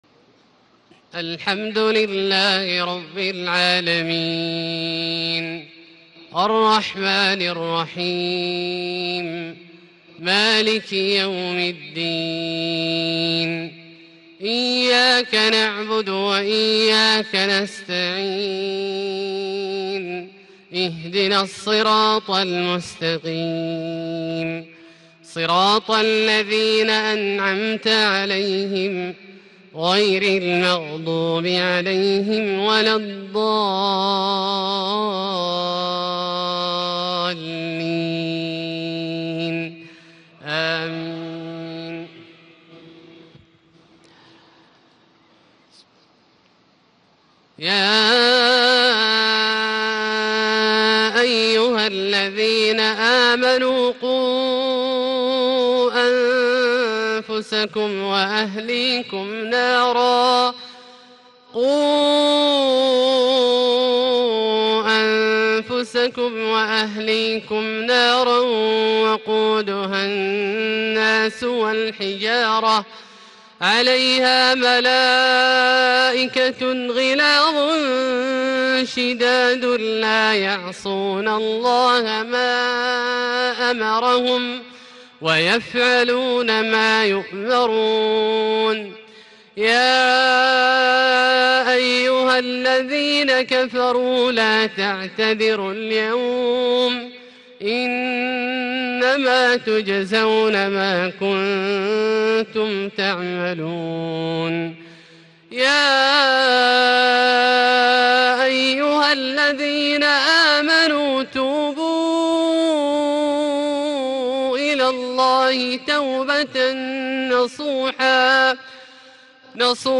صلاة العشاء 22 ذو القعدة 1437هـ من سورة التحريم 6-12 > 1437 🕋 > الفروض - تلاوات الحرمين